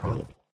tbd-station-14/Resources/Audio/Voice/Talk/vulp.ogg